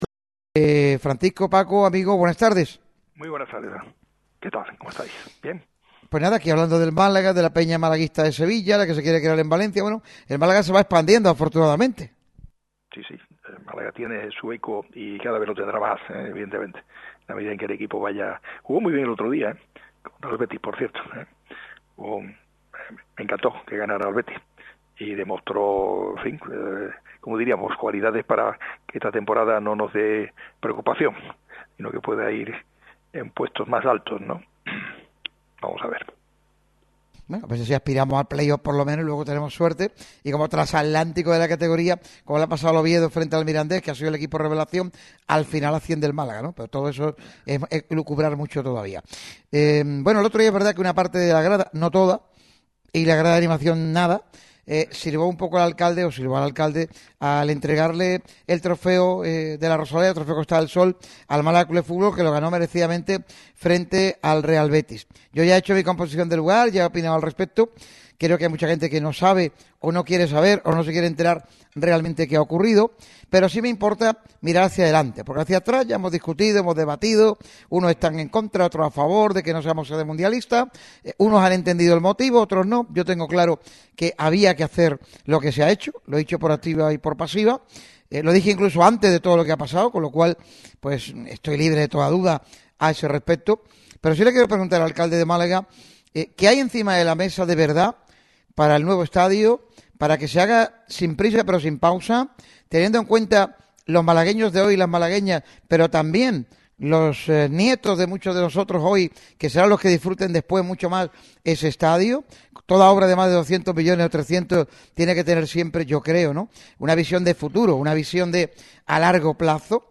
Entrevistón en la radio del deporte. El alcalde de la capital de la Costa del Sol ha comparecido en el micrófono rojo en una entrevista cargada de temas a tratar. El primero y principal, la renuncia por parte de Málaga a ser parte como sede del Mundial 2030.